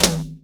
TOM     1B.wav